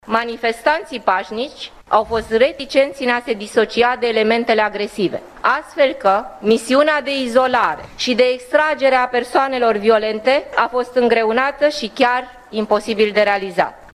Carmen Dan a sintetizat însă, în declaraţia de presă, unele elemente ale documentului. Spre deosebire de alte dăţi, a spus Carmen Dan, comportamentul masei protestatarilor a fost acum diferit: